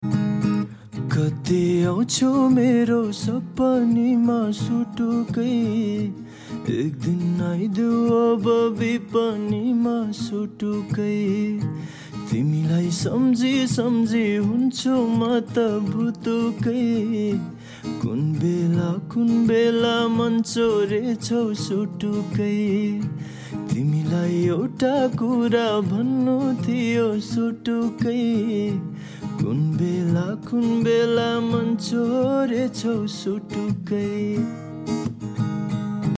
raw cover song